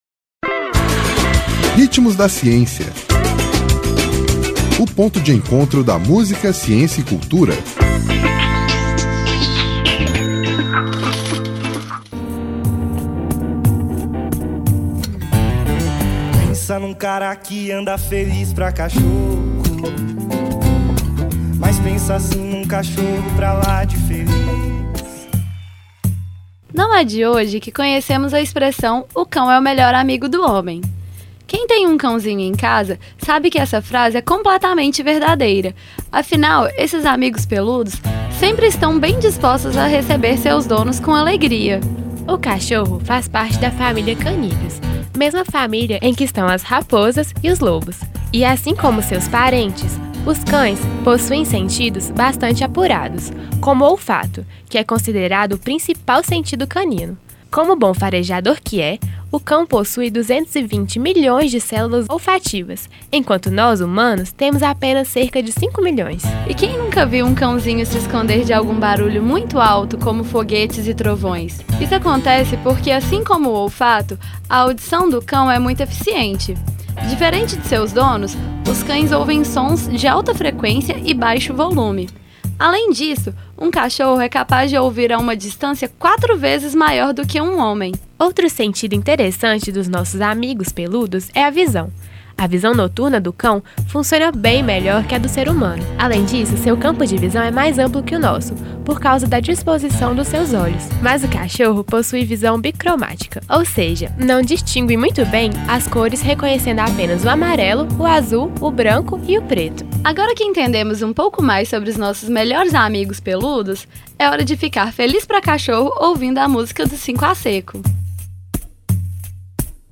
Nome da música: Feliz Pra Cachorro
Intérprete: 5 à Seco